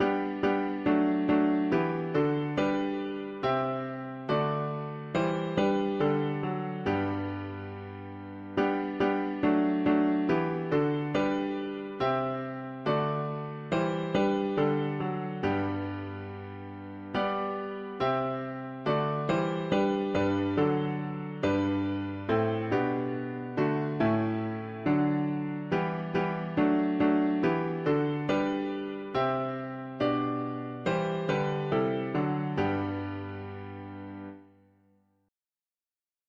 God hath brought forth Israel into joy from sadness, loosed from Pharaeh’s bitter yoke Jac… english christian easter 4part chords